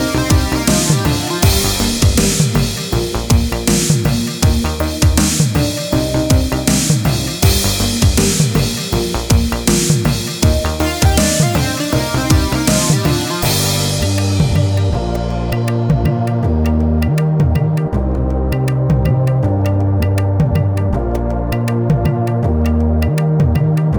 no Backing Vocals at all Pop (2010s) 3:52 Buy £1.50